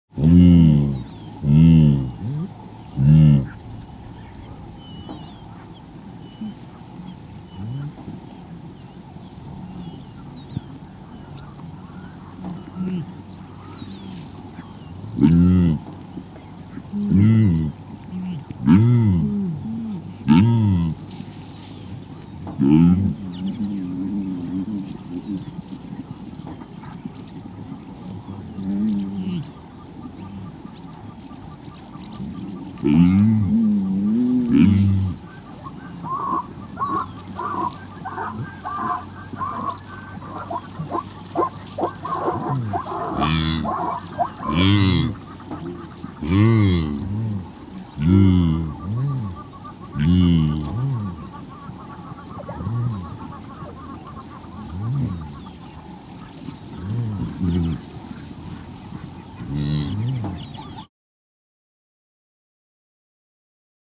جلوه های صوتی
دانلود صدای گاو وحشی 2 از ساعد نیوز با لینک مستقیم و کیفیت بالا